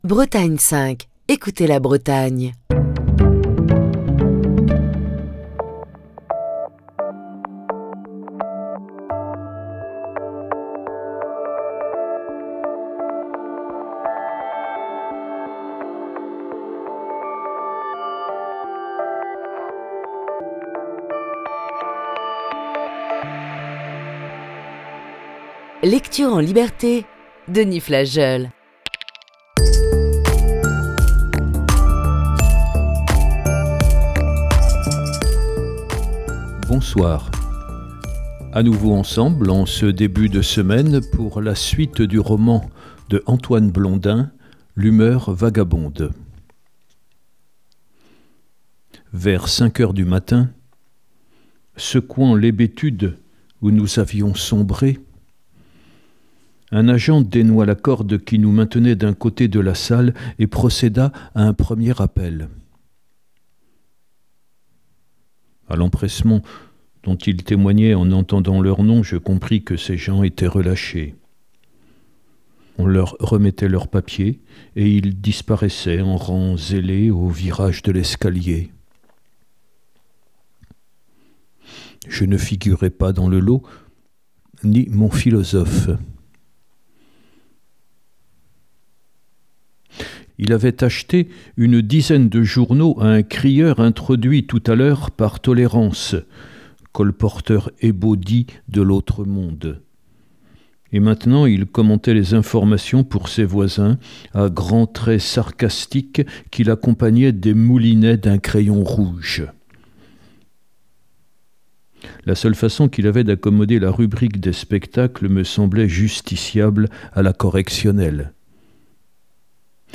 Voici ce lundi la lecture de la sixième partie de ce récit.